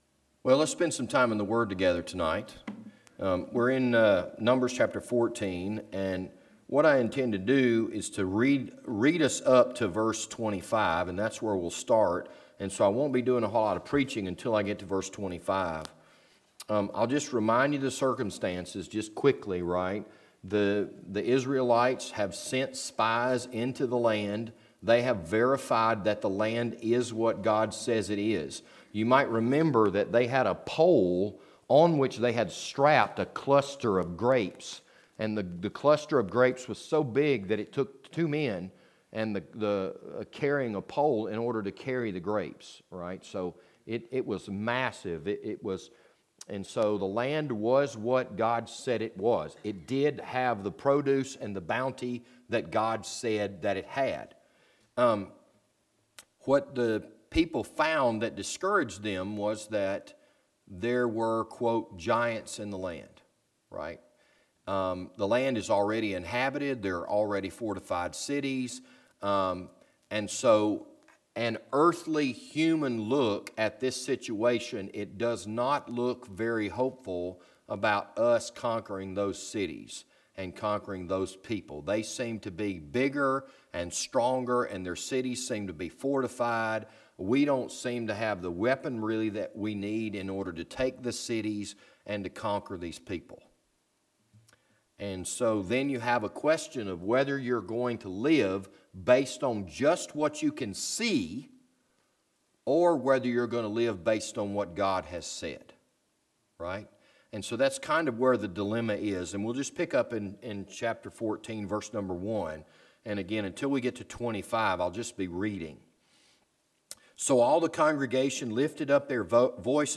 This Sunday evening sermon was recorded on October 28, 2018.